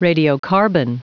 Prononciation du mot radiocarbon en anglais (fichier audio)
radiocarbon.wav